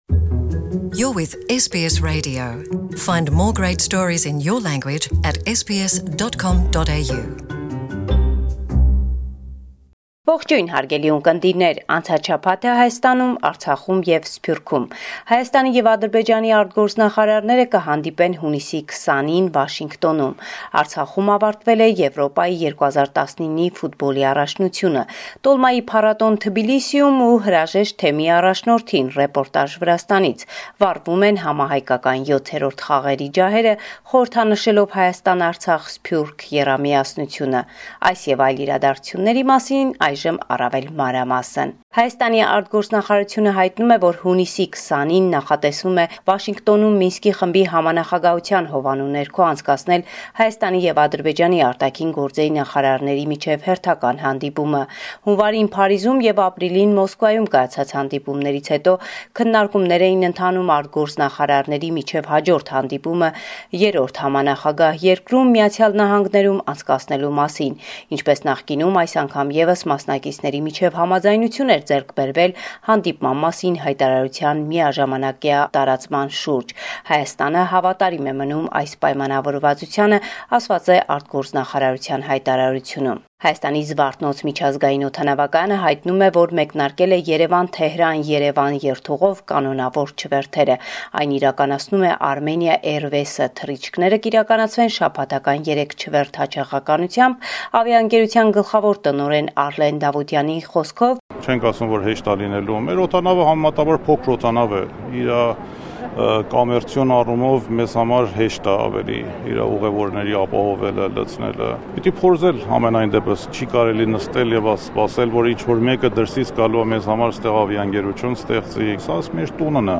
Latest News – 18 June 2019